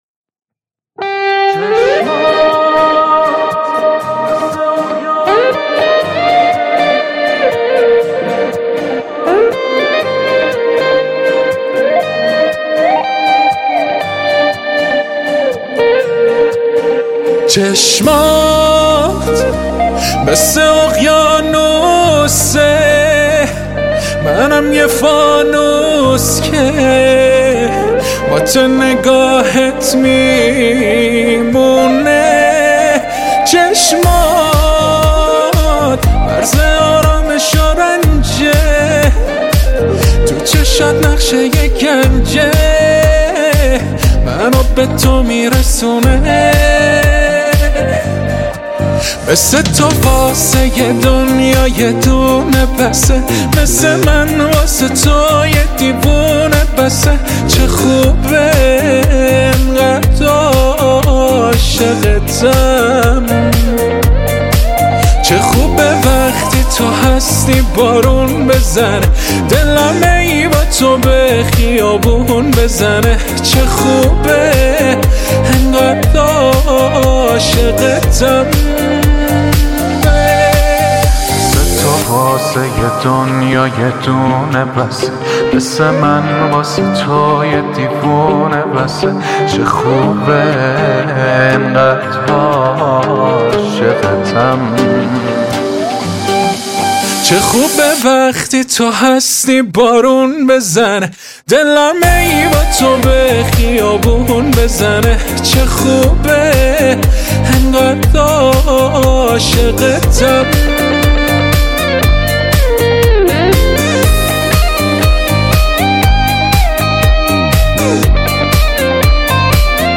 خیلی هممممم عالی ریتم اهنگتو دوس داشتم‌بیت قشنگی داری .مرسی